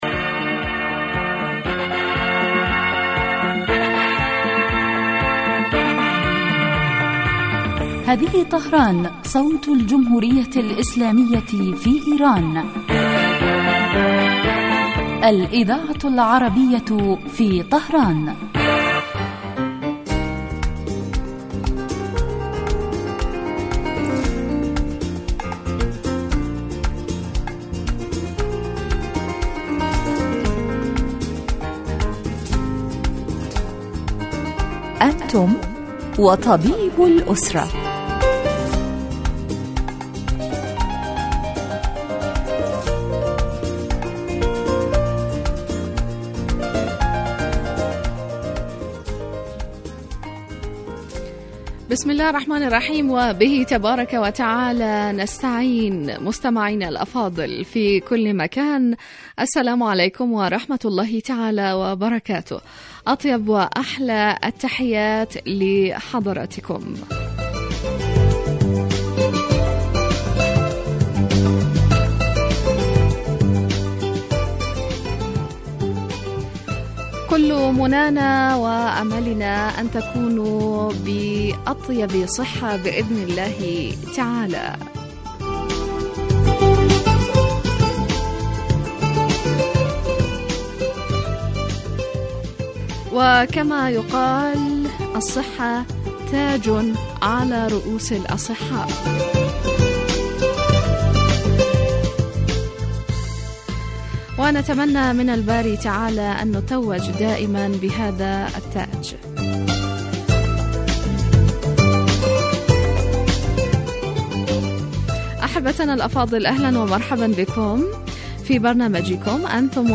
یتناول البرنامج بالدراسة والتحليل ما یتعلق بالأمراض وهو خاص بالأسرة ویقدم مباشرة من قبل الطبیب المختص الذي یرد کذلک علی أسئلة المستمعین واستفساراتهم الطبیة